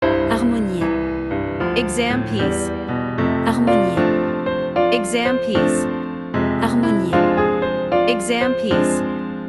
• 人声数拍
• 大师演奏范例